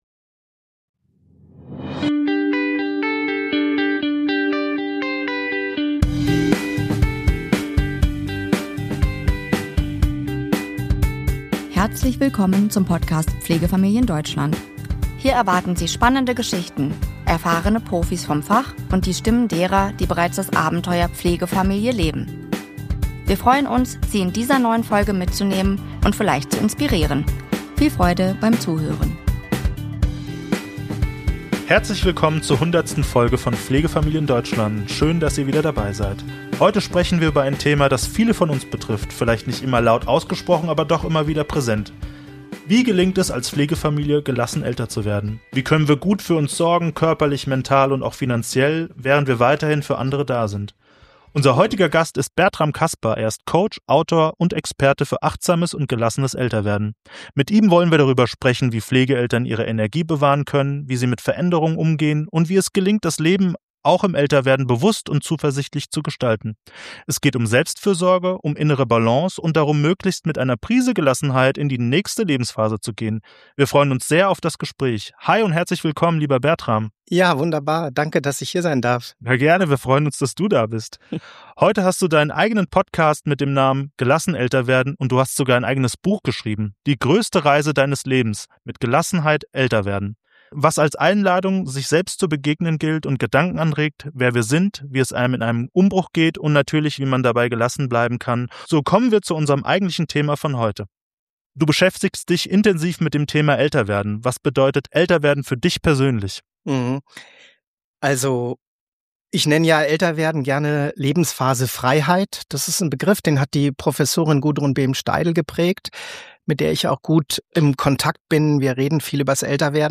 Es geht um Selbstfürsorge, um innere Balance und darum möglichst mit einer Prise Gelassenheit in die nächsten Lebensphasen zu gehen. Wir freuen uns sehr auf das Gespräch.